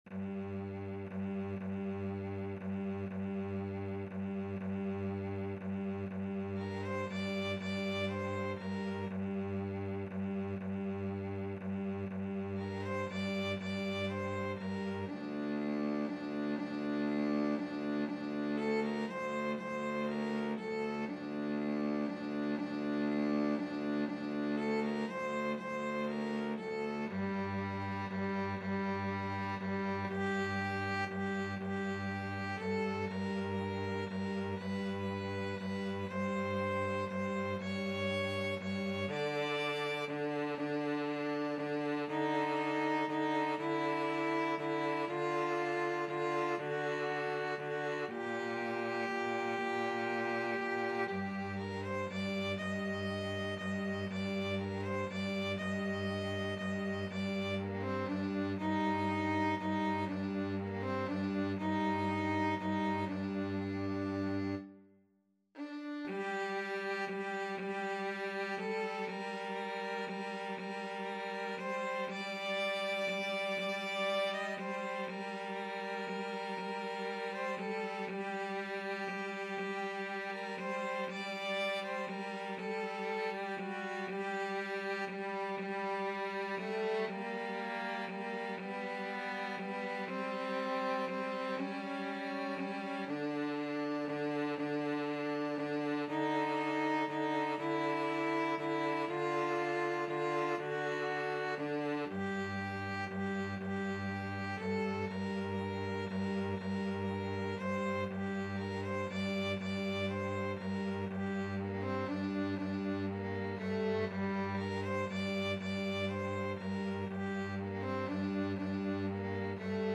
12/8 (View more 12/8 Music)
= 60 Andante, con piombi
Classical (View more Classical Violin-Cello Duet Music)